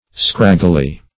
scraggily - definition of scraggily - synonyms, pronunciation, spelling from Free Dictionary
scraggily - definition of scraggily - synonyms, pronunciation, spelling from Free Dictionary Search Result for " scraggily" : The Collaborative International Dictionary of English v.0.48: Scraggily \Scrag"gi*ly\, adv. In a scraggy manner.